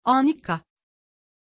Aussprache von Anikka
pronunciation-anikka-tr.mp3